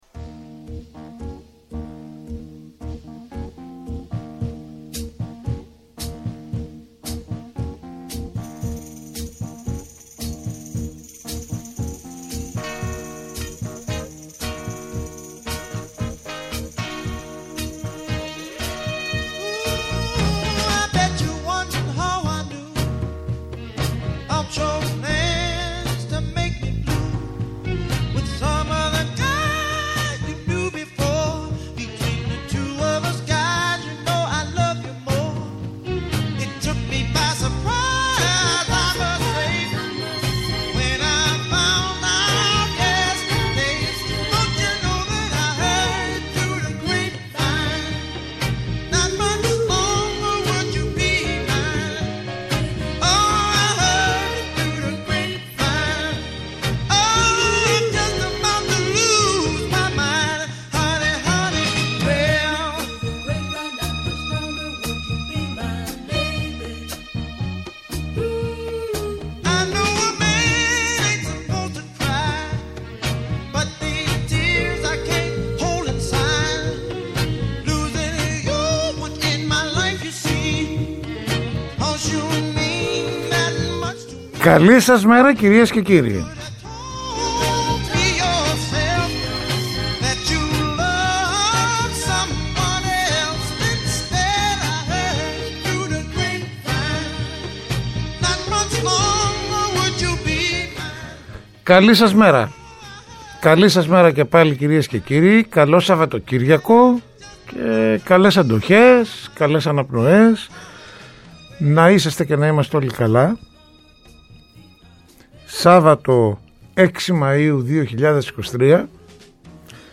-Ο Σταύρος Καλαφάτης, υποψήφιος βουλευτής ΝΔ
-Ο Νίκος Παππάς, υποψήφιος βουλευτής ΣΥΡΙΖΑ